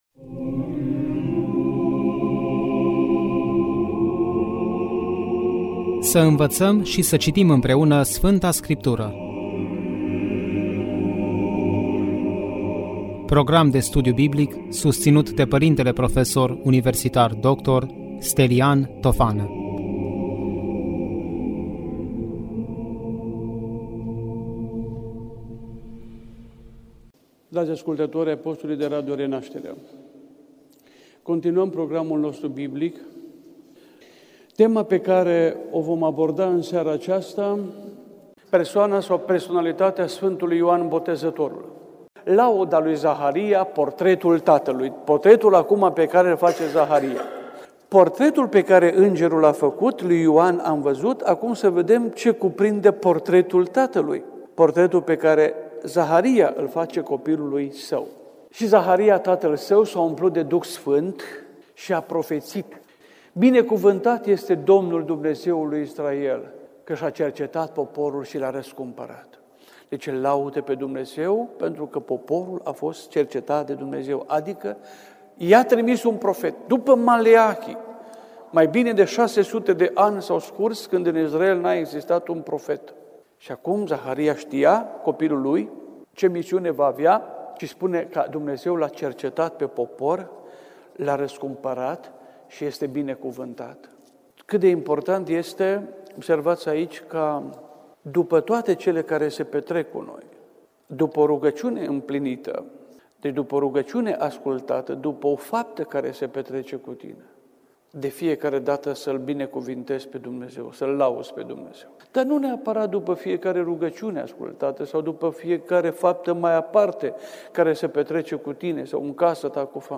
Studiu Biblic Cine a fost Sfântul Ioan Botezătorul?